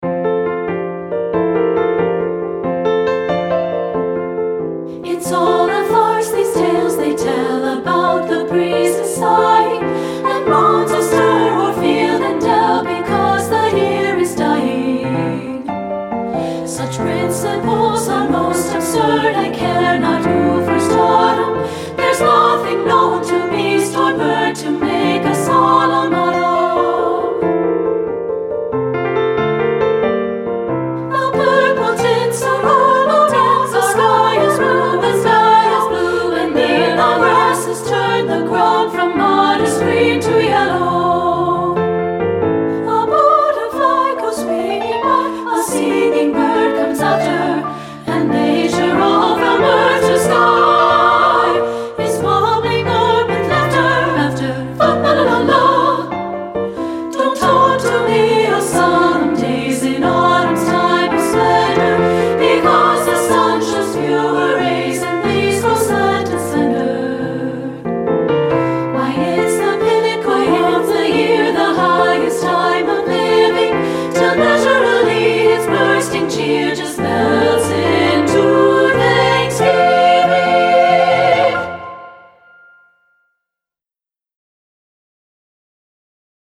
• Soprano 1
• Soprano 2
• Alto
• Piano
Studio Recording
Delightfully delicate
should be sung in a lilting meter of two
Ensemble: Treble Chorus
Accompanied: Accompanied Chorus